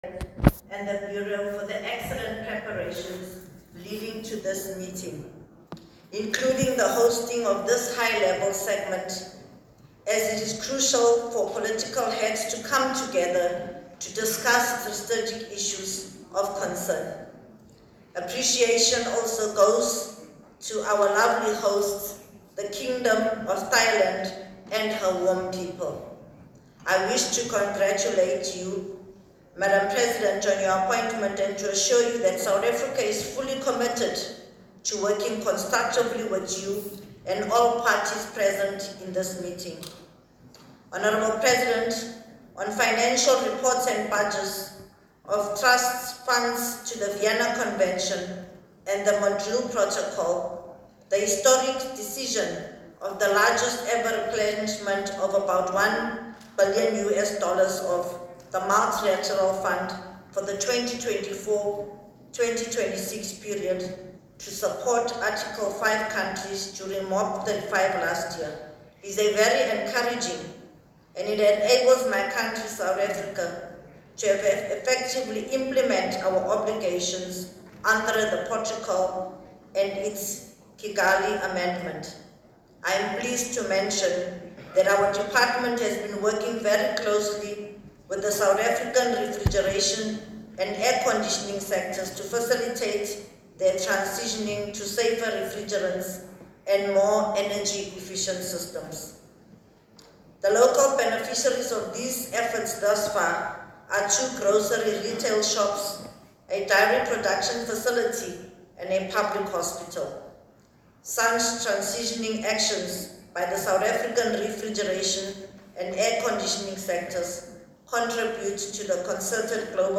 Deputy Minister B Swarts: Ministerial statement at the 13th Conference Of The Parties (CoP 13), and the 36th Meeting of the Parties to the Montreal Protocol on substances that deplete the ozone layer (MoP36)
31 October 2024, Bangkok, Thailand
Deputy Minister B Swarts at MOP 36 in Bangkok Thailand
swarts_statementat_mop36_thailand.m4a